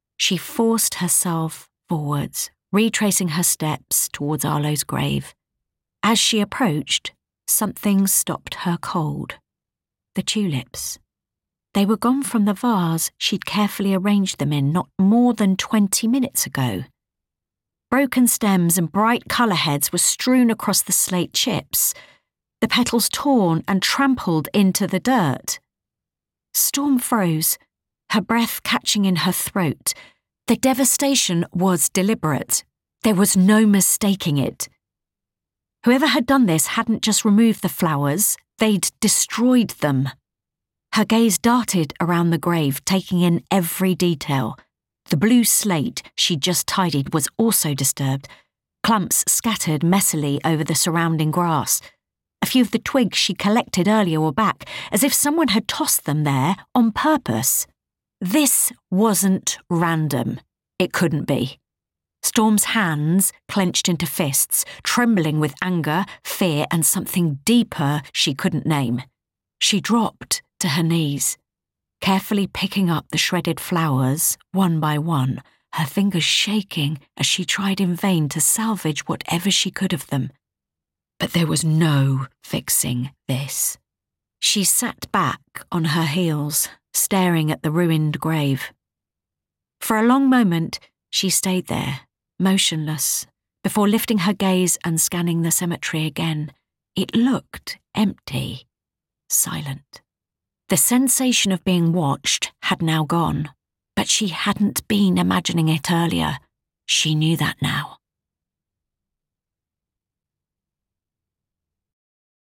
40's Neutral/London,
Warm/Reassuring/Calm
• Audio Books